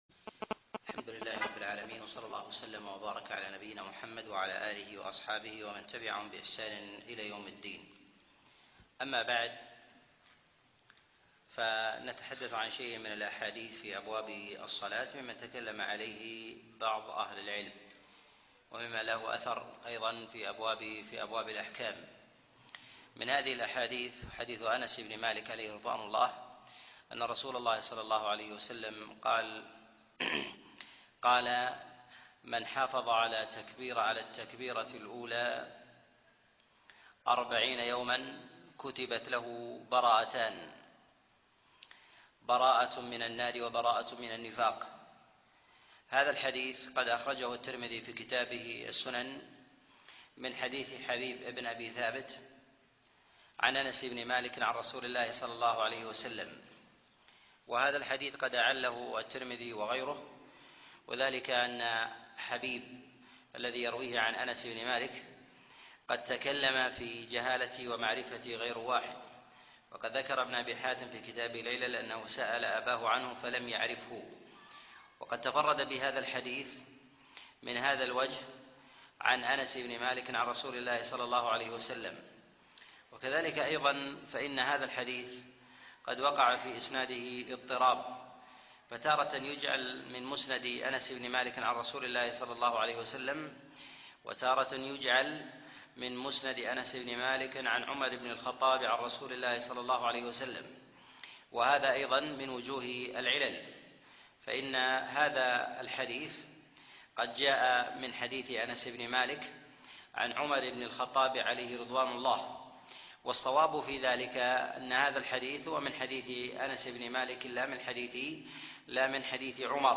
الأحاديث المعلة في الصلاة الدرس 28